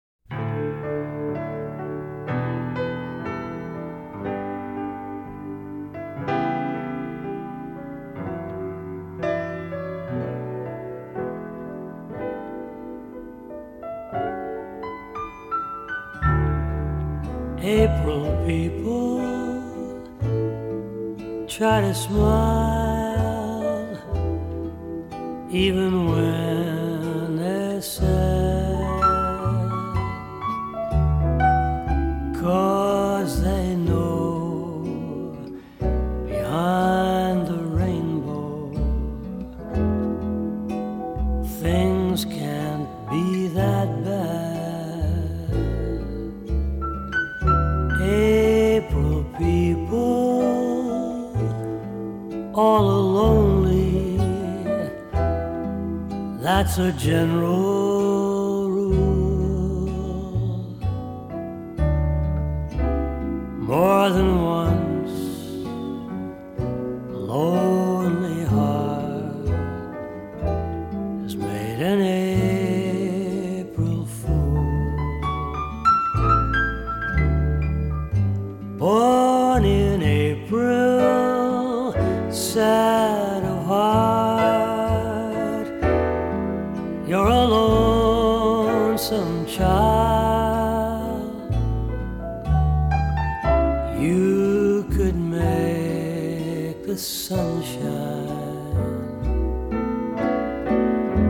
★柔和而富磁性的歌聲，輕緩悠揚的爵士風情，為您在夜間點上一盞綻放溫暖光芒的燈。
低沉富磁性的嗓音更增添了歲月的風采，傳統爵士的鋼琴、吉他、薩克斯風外，更以大提琴、笛子等增添浪漫悠揚的感性